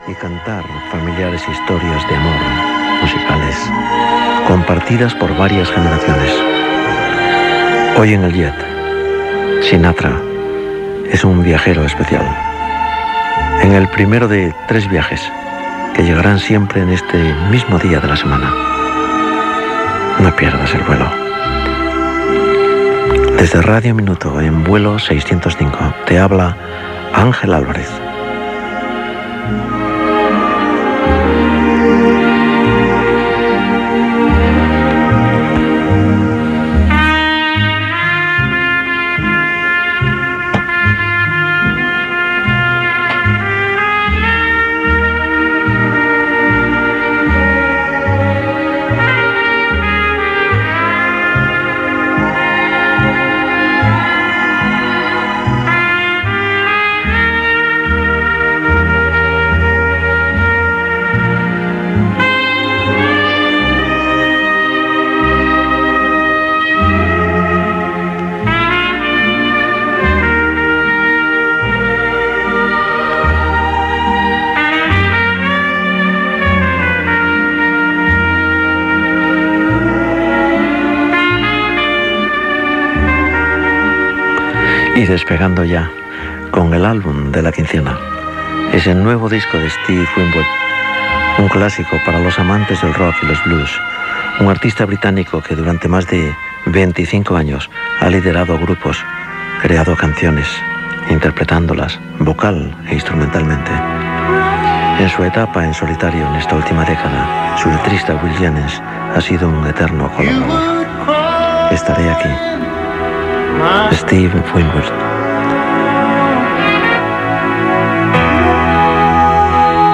Identificació del programa i de la cadena, tema musical, presentació de l'àlbum de la quinzena.
Musical